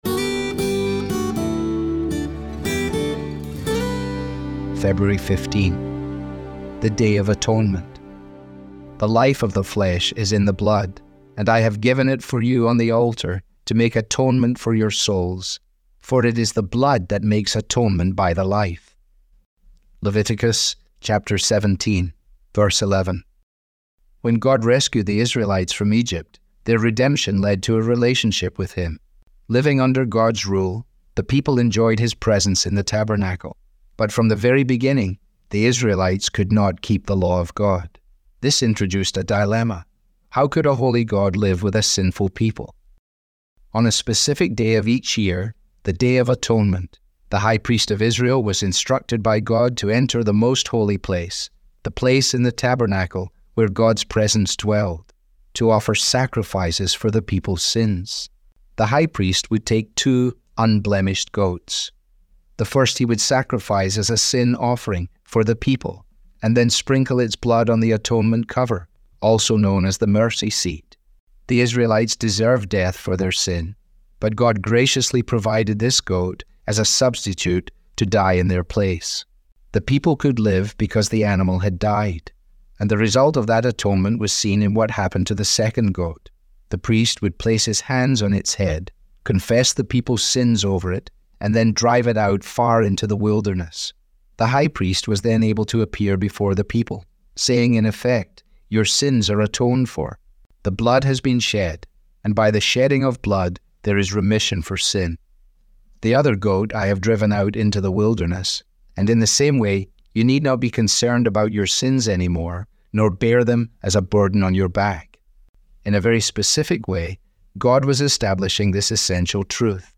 Audio was digitally created by Truth For Life with permission.